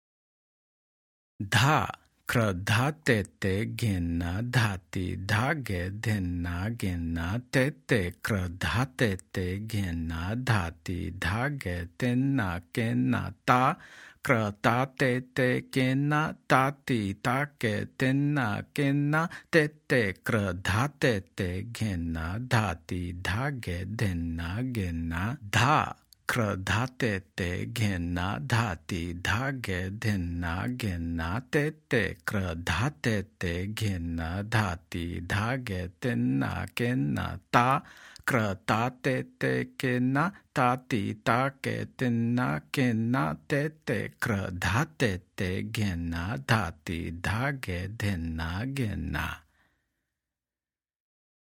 Demonstrations
Spoken – Slow